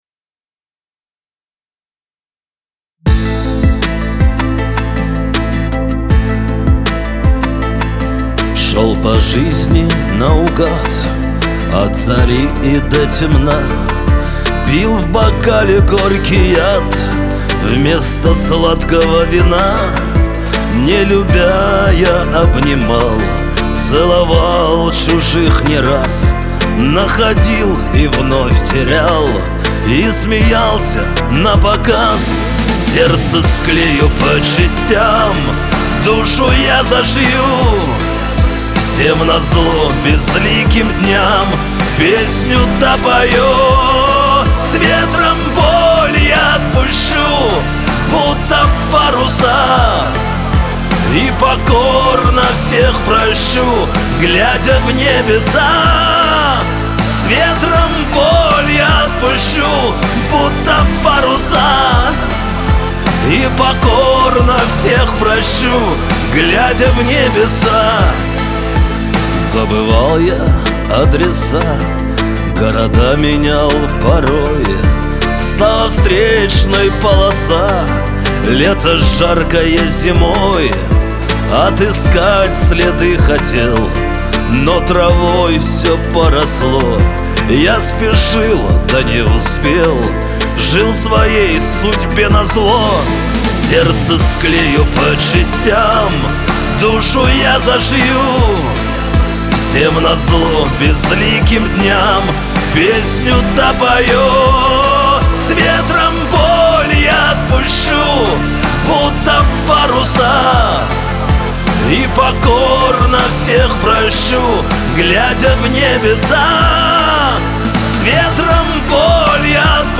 эстрада , pop , Лирика